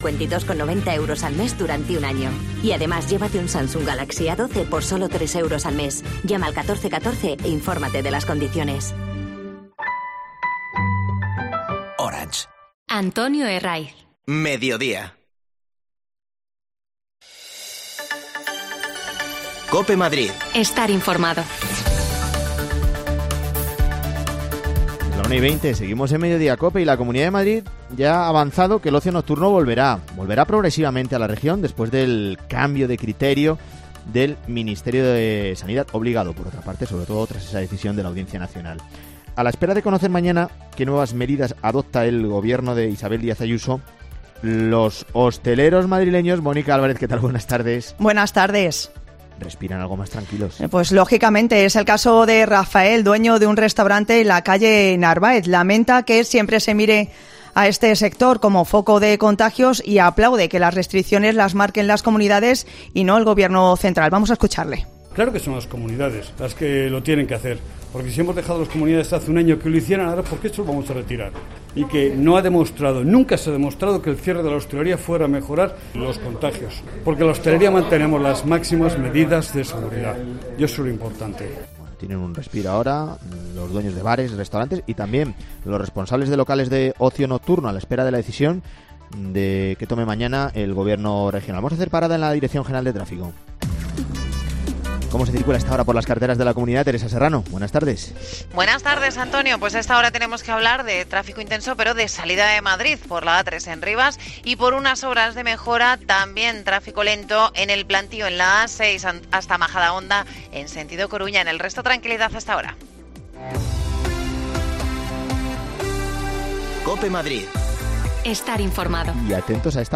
AUDIO: Los hosteleros madrileños respiran más tranquilos después del paso atrás de Sanidad relajando las medidas anticovid. Les escuchamos
Las desconexiones locales de Madrid son espacios de 10 minutos de duración que se emiten en COPE , de lunes a viernes.